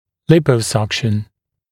[‘lɪpəuˌsʌkʃn][‘липоуˌсакшн]липосакция